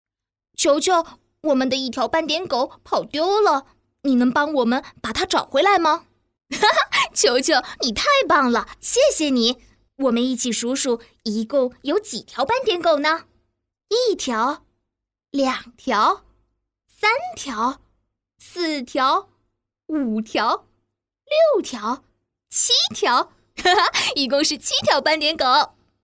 女声配音